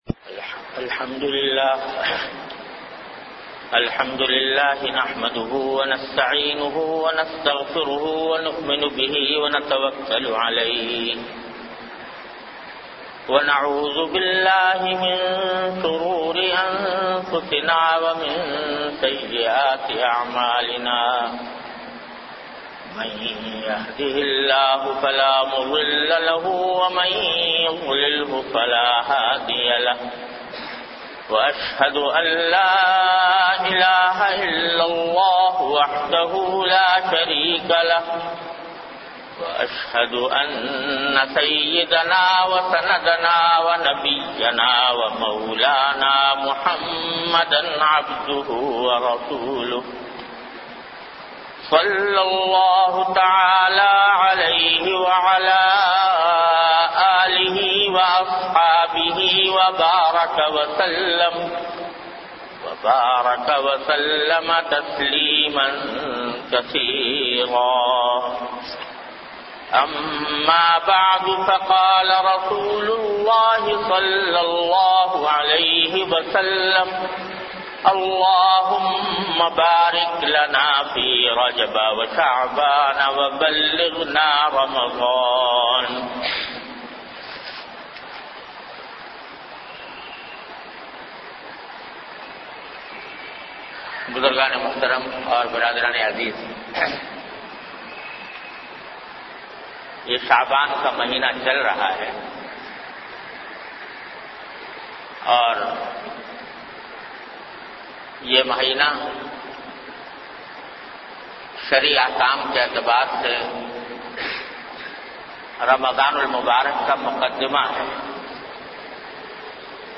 An Islamic audio bayan by Hazrat Mufti Muhammad Taqi Usmani Sahab (Db) on Bayanat. Delivered at Jamia Masjid Bait-ul-Mukkaram, Karachi.